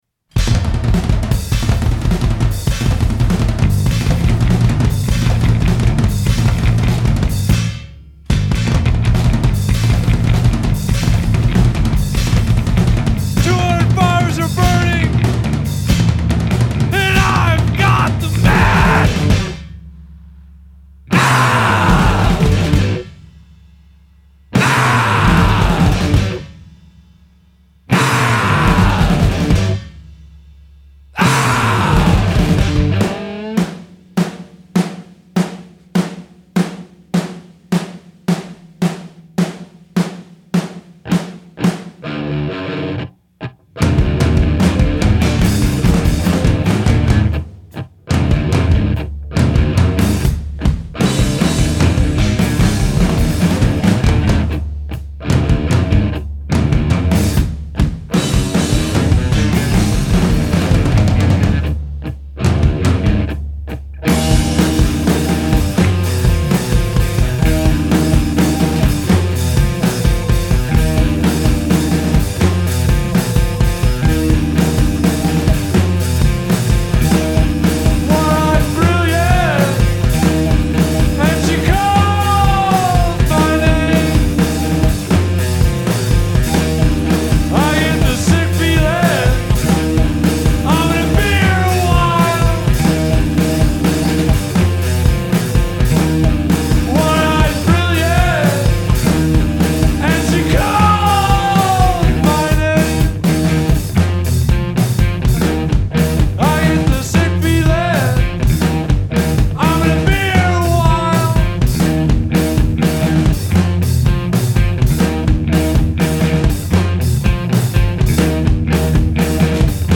En dat ondanks de standaard line-up van zanger
gitarist
bassist
en drummer